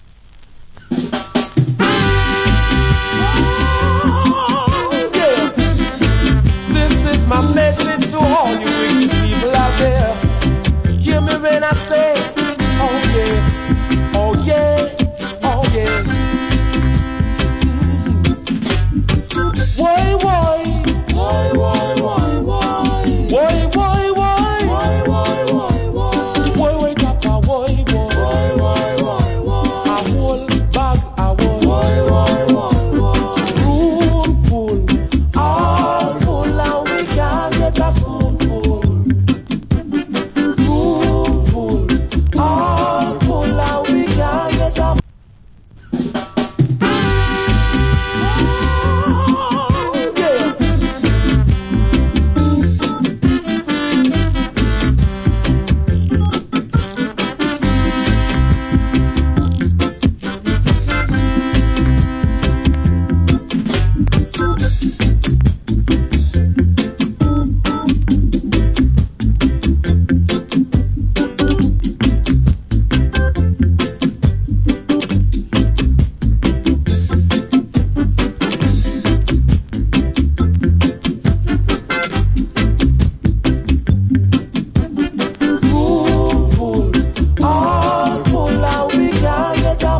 Cool Reggae.